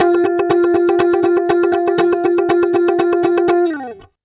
120FUNKY10.wav